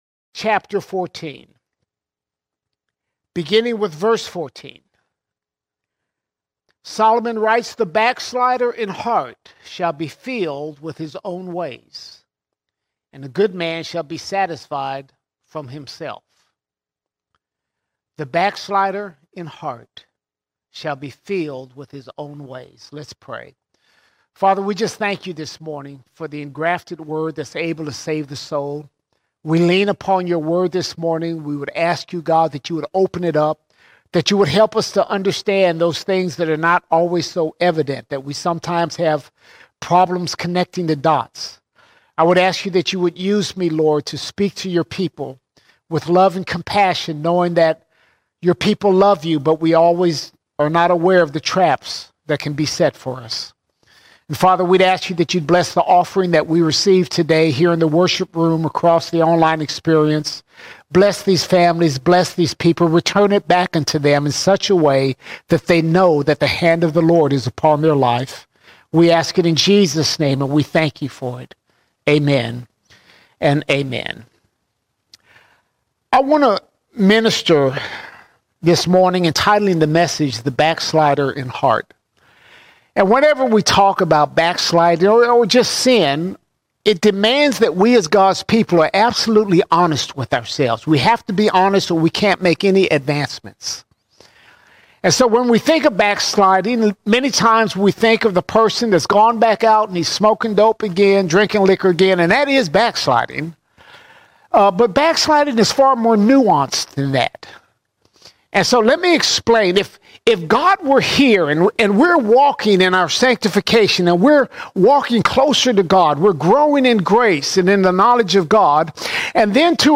20 January 2025 Series: Sunday Sermons All Sermons The Backslider in Heart The Backslider in Heart Explore the meaning of backsliding, its causes, and practical ways to avoid falling into this spiritual decline.